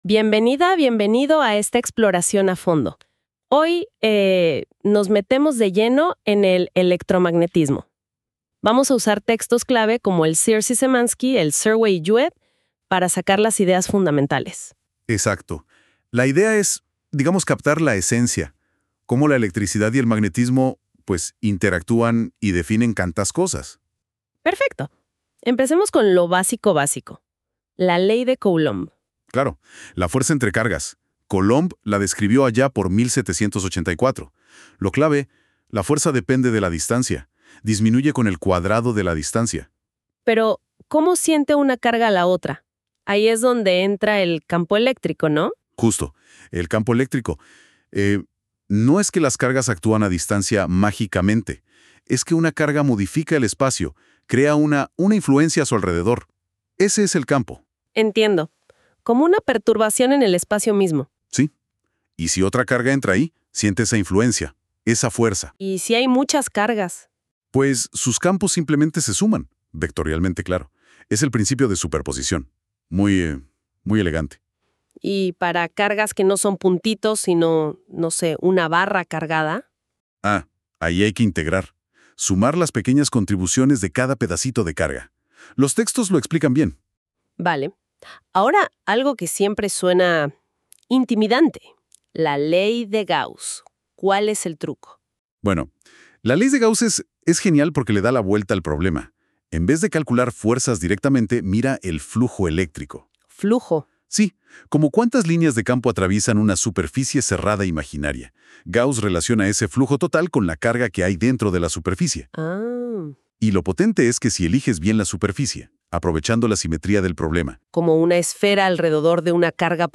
El presente material ha sido generado mediante NotebookLM, una herramienta de inteligencia artificial desarrollada por Google.